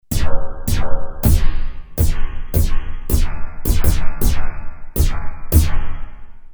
Double set of cards for both synthesizer Roland JD-800 or module JD-990.
A-48. Alien Toms   A-51.
2-alientoms.mp3